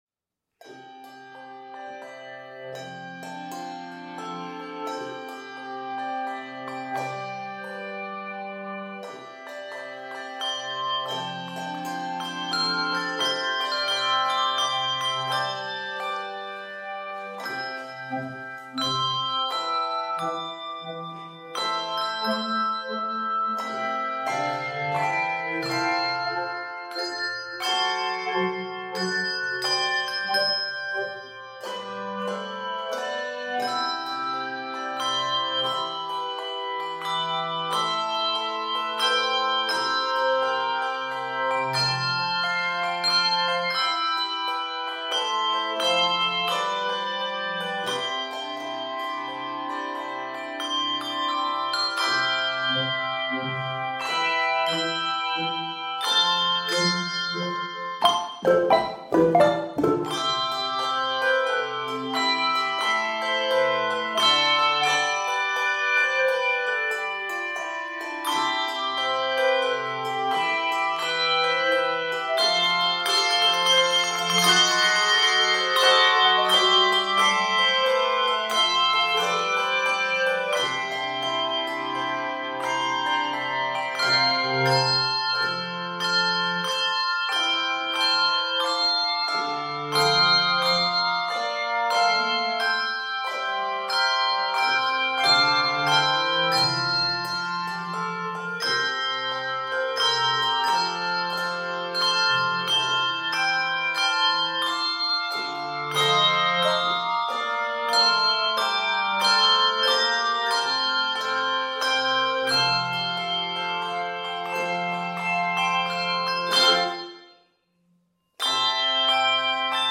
Using swing rhythms and non-traditional harmonies
gospel hymns